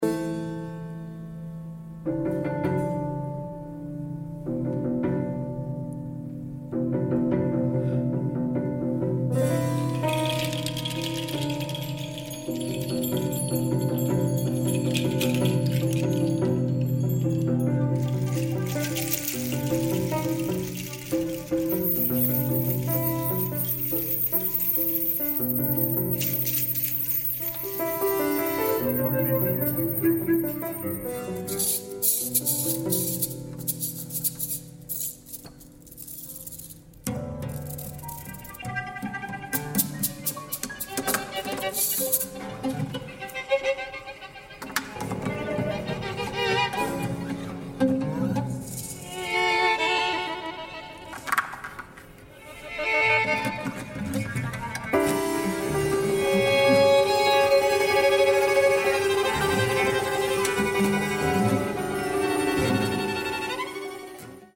extended piano, oud, violin, cello and percussion
experimental chamber ensemble
Recorded October 1, 2024 at Miller Theatre, NYC